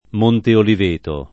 m1nte oliv%to] o Monteoliveto [id.] top. — sempre in gf. divisa l’abbazia benedettina (olivetana) di Monte Oliveto Maggiore (Tosc.) — nell’una gf. o nell’altra il Monte Oliveto o Monteoliveto di Firenze (uffic. in gf. divisa) e quello di Napoli (uffic. in gf. unita)